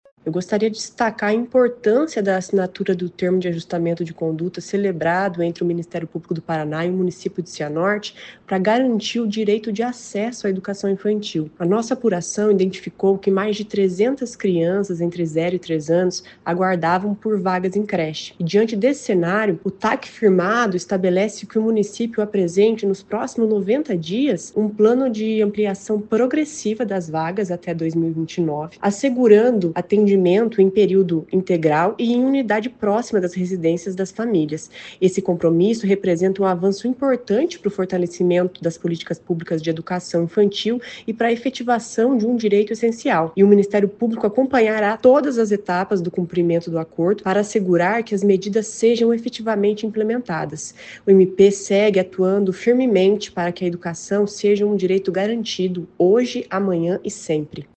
Ouça o que diz a promotora de Justiça Hellen Cristina Pereira Painelli.